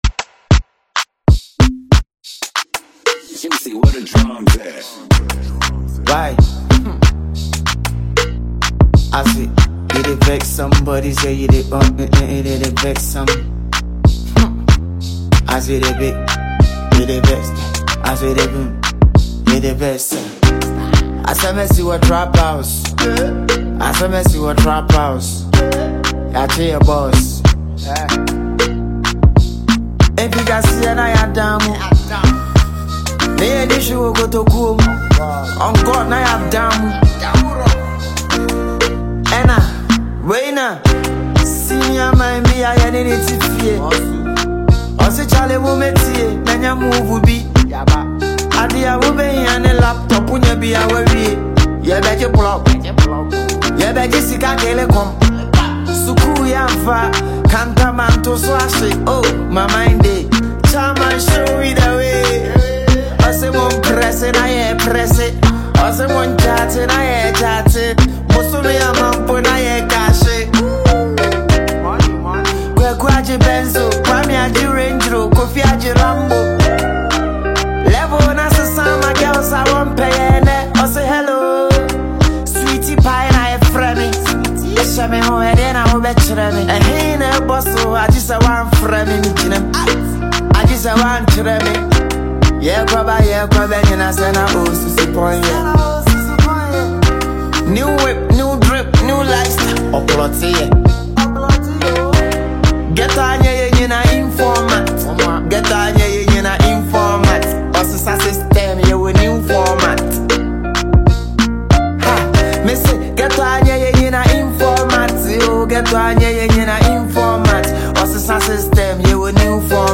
• Genre: Hip-hop / Trap